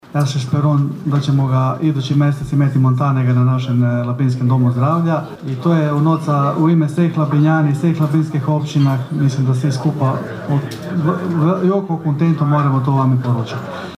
Stotinjak iseljenika koji trenutačno borave u domovini, uglavnom iz SAD-a, ali i Kanade te europskih zemalja Francuske i Njemačke, okupilo se sinoć u Konobi 'Bukaleta' na Dubrovi, na Godišnjem susretu iseljenika Labinštine.
Pozdravljajući iseljenike gradonačelnik Valter Glavičić im je ponajprije zahvalio za pomoć u nabavci mamografa: (